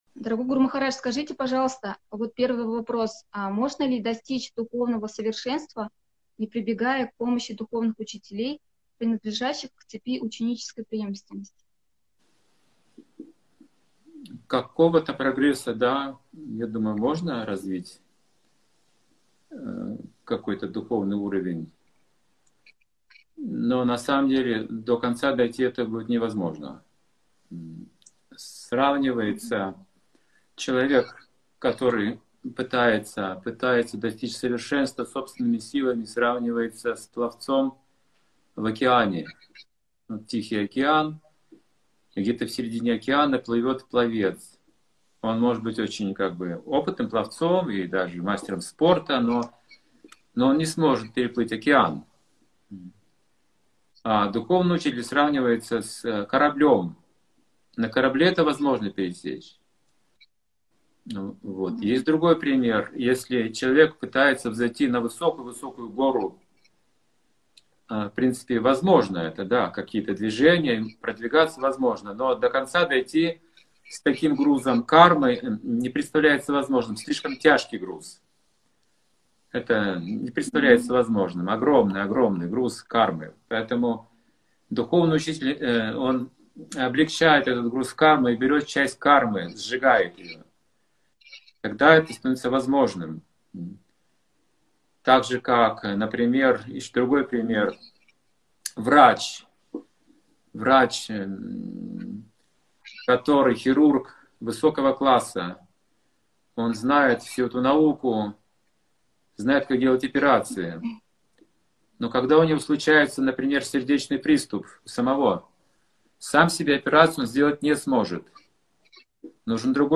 Алматы